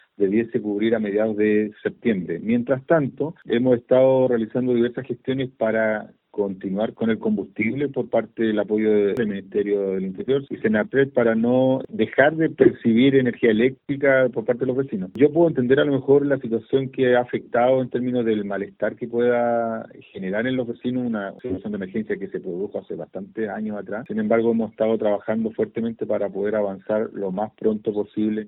El alcalde de Cochamó, Francisco Donoso, dijo que en septiembre debería estar reparándose el sistema eléctrico.
turbina-llanada-grande-alcalde.mp3